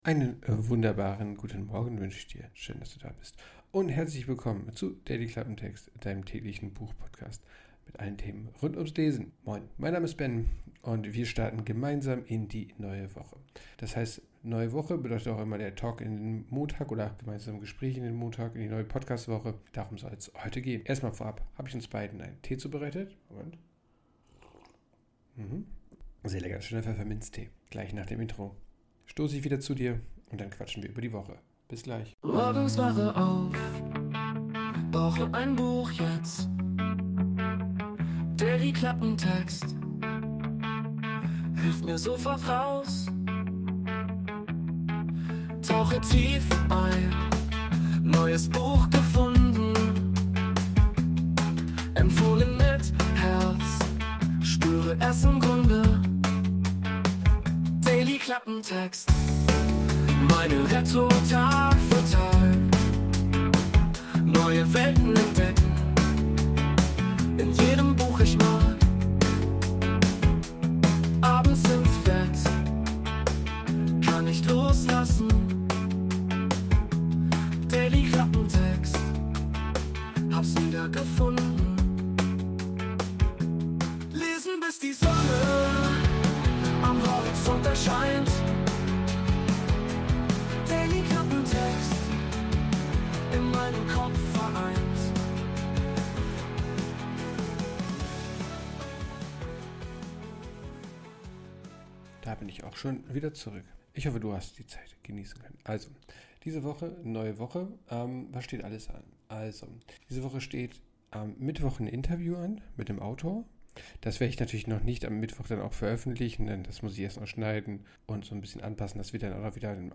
Folge 211: Talk in die Woche - Blick in die Woche | Talk
Intromusik: Wurde mit der KI Suno erstellt.